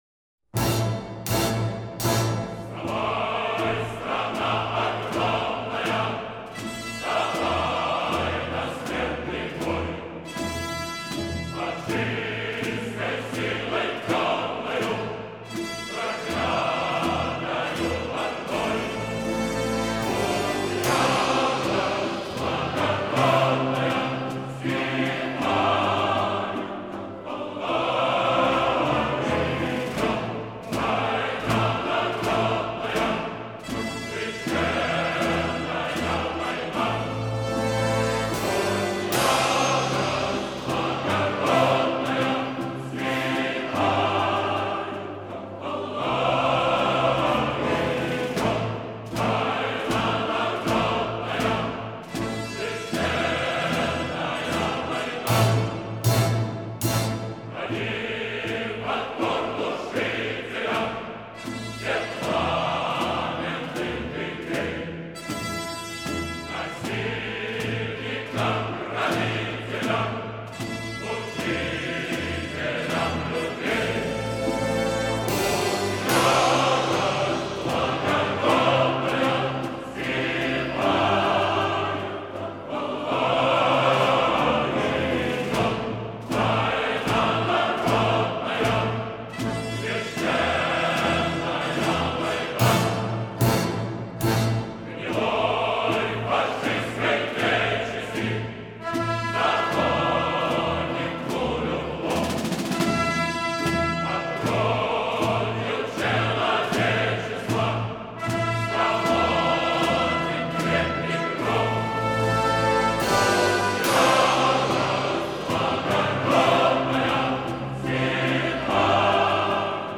这首歌有一个特色，在节奏上是三拍子写成，却具有队列进行曲般的二拍子的特征。